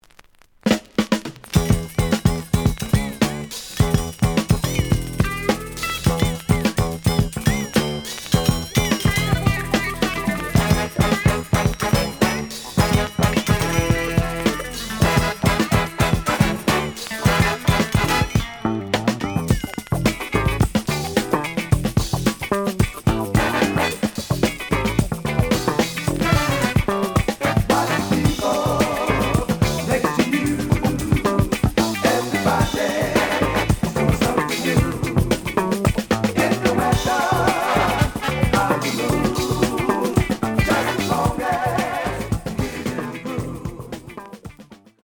試聴は実際のレコードから録音しています。
The audio sample is recorded from the actual item.
●Genre: Funk, 70's Funk
B side plays good.)